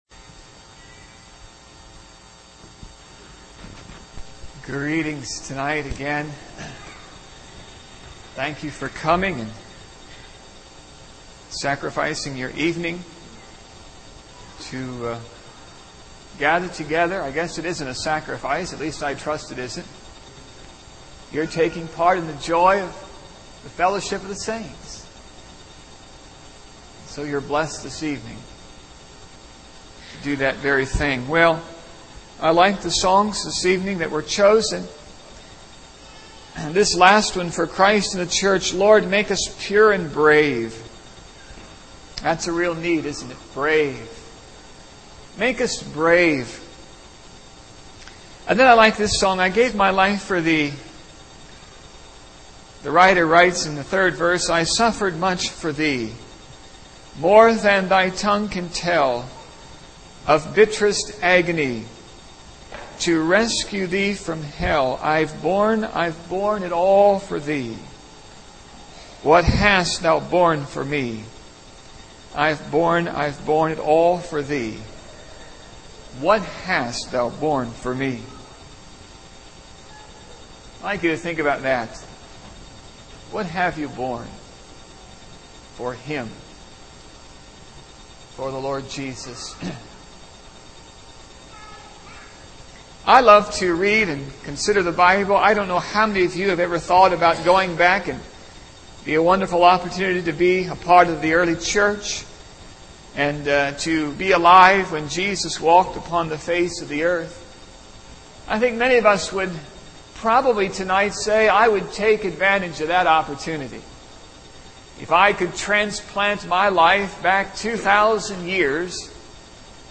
Service Type: Saturday Evening